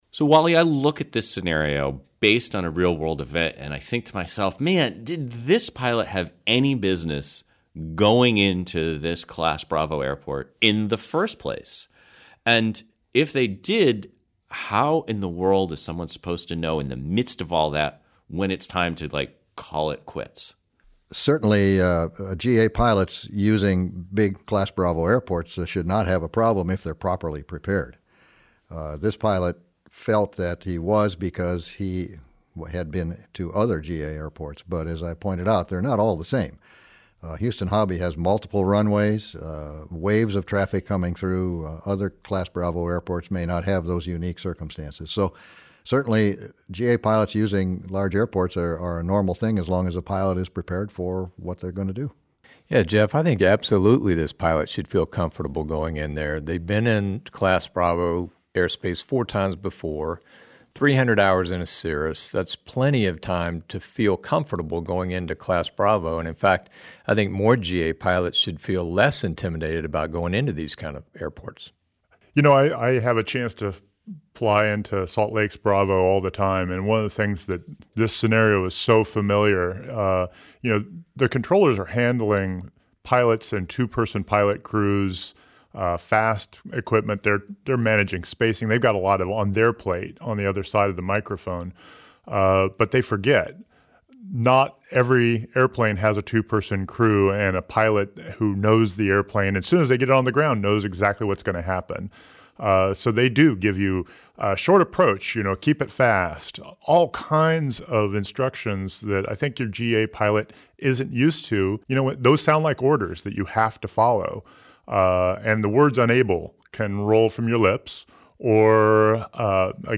Runway Roulette at Hobby _ roundtable.mp3